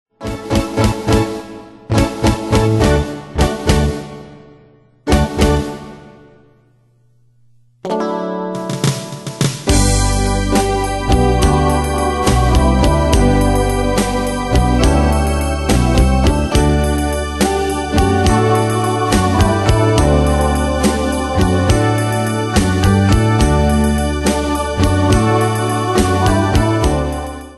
Style: Retro Année/Year: 1965 Tempo: 70 Durée/Time: 2.06
Danse/Dance: Ballade Cat Id.
Pro Backing Tracks